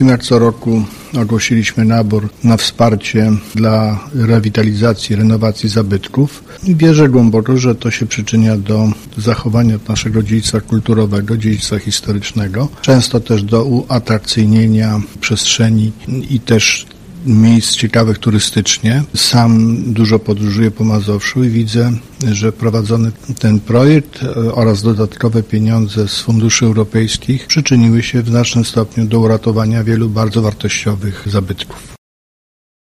– mówił marszałek Adam Struzik.